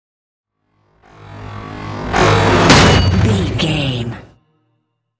Electronic whoosh to metal hit
Sound Effects
Atonal
futuristic
tension
woosh to hit